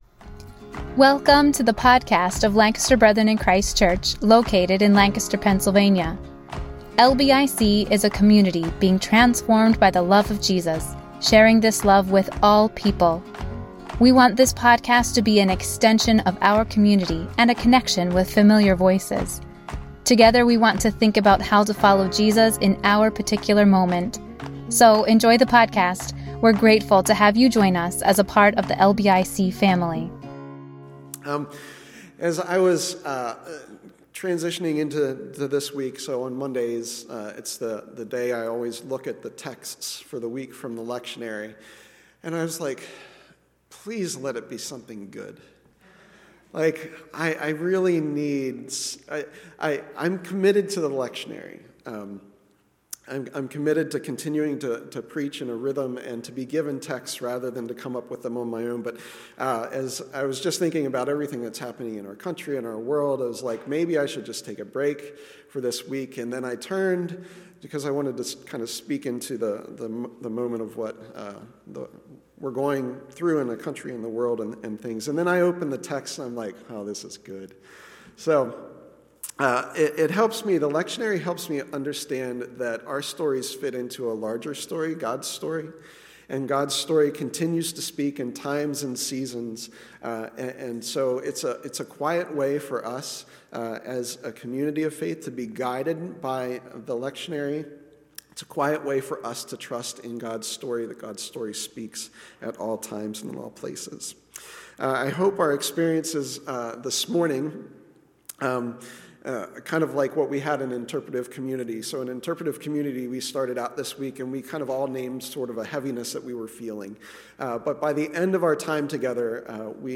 Hoping for the Scriptures to Speak Service Message